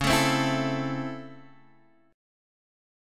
DbmM7#5 Chord